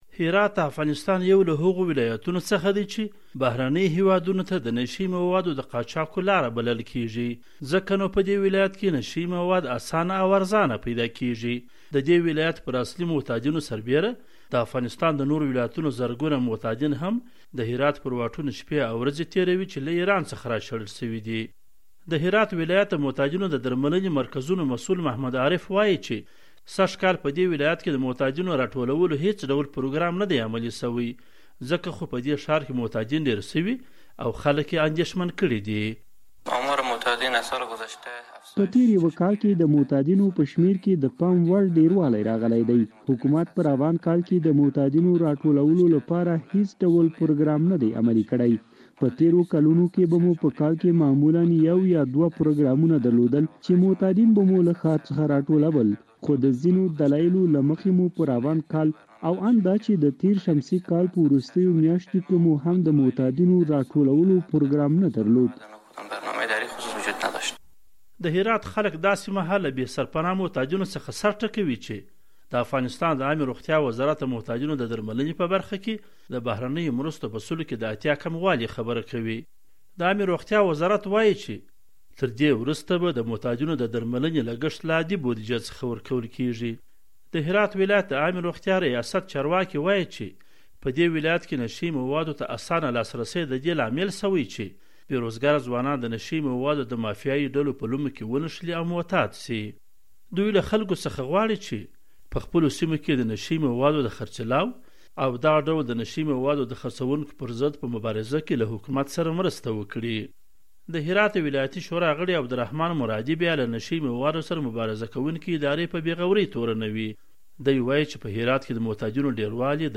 د زهرو کاروان-راپور